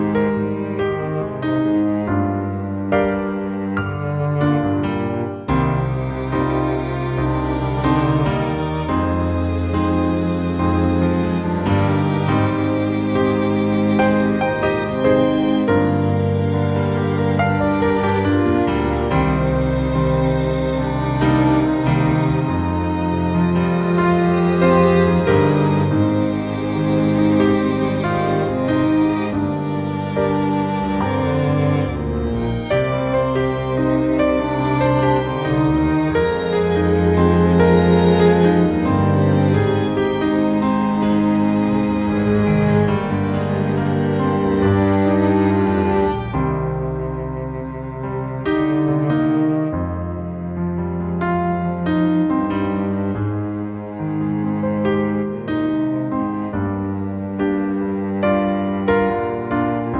Up 5 Semitones For Female